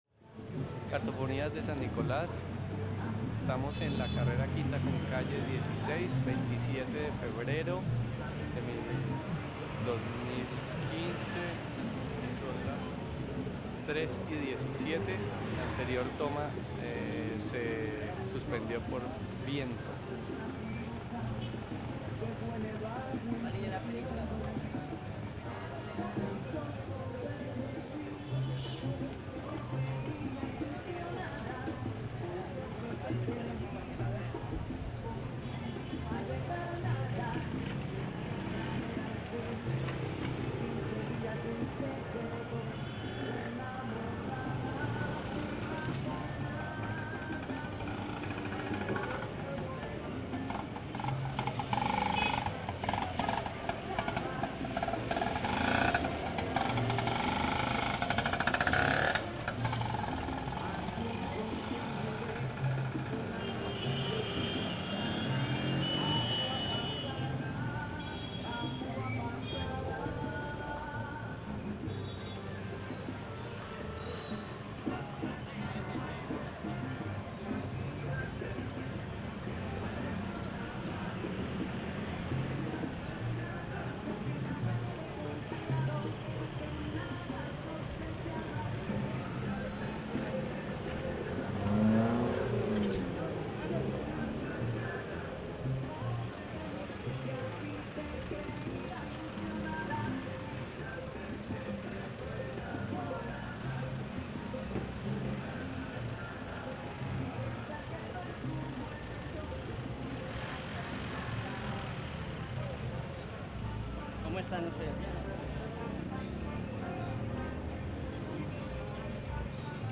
Audio pdc_Sannicolas_2012.mp3 Resumen Descripción Cartofonías de San Nicolás Estudios sobre la memoria sonora de la industria gráfica en Cali Cargando...